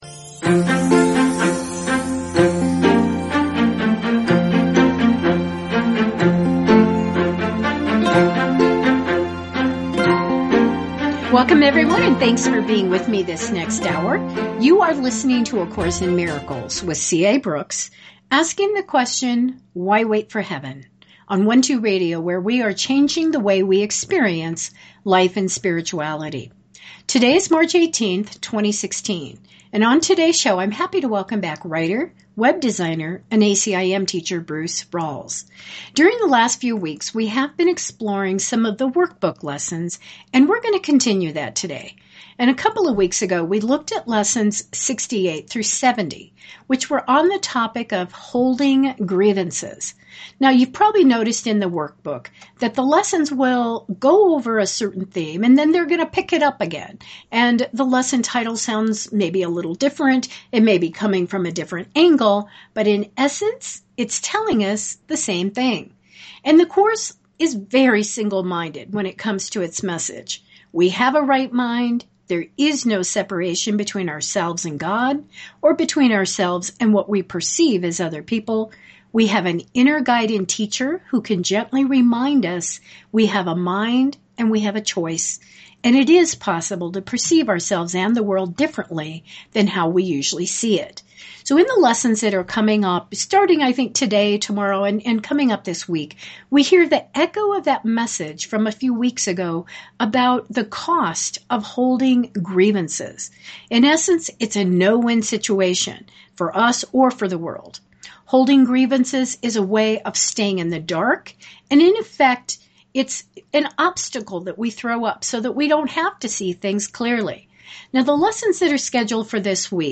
internet radio program